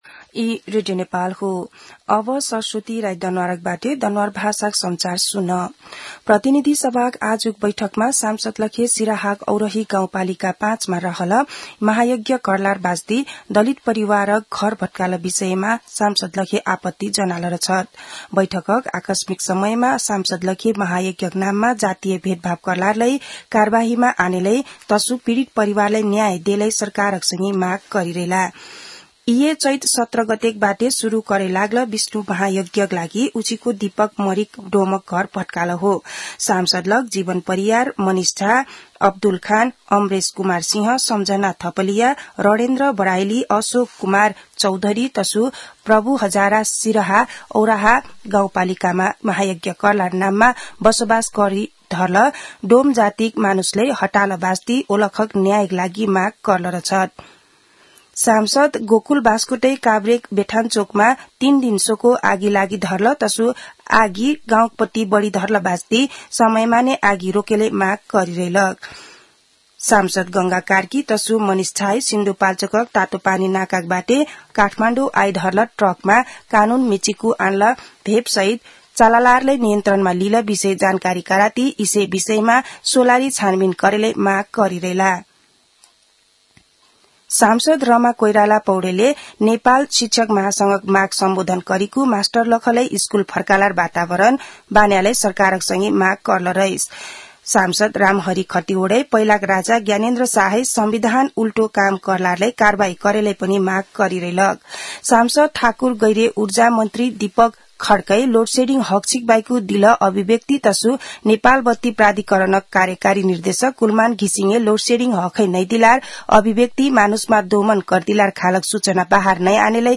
दनुवार भाषामा समाचार : ७ चैत , २०८१
danuwar-news-2.mp3